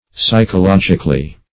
Psy`cho*log"ic*al*ly, adv.